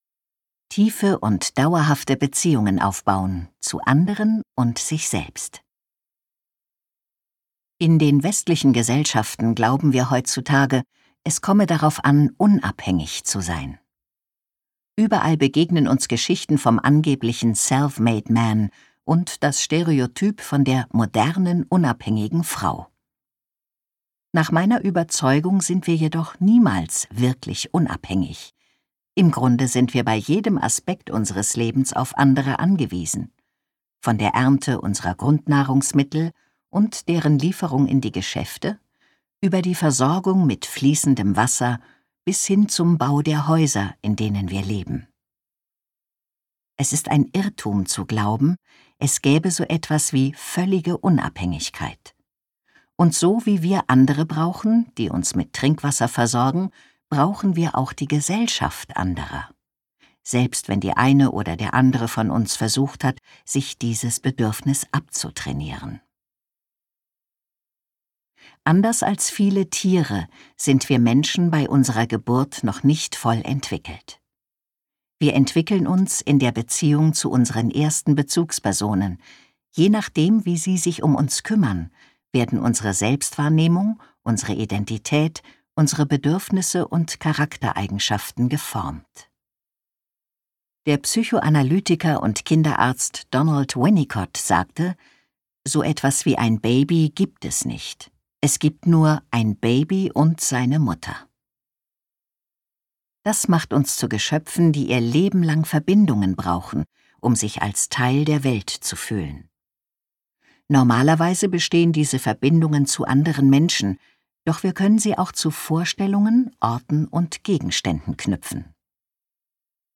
Schlagworte Angewandte Psychologie • Beziehung • Bindungsstil • Entwicklung • Hörbuch Ratgeber • Hörbuch ungekürzt • Kommunikation • Persönlichkeitsentwicklung • richtig streiten • Sachhörbuch • zufrieden leben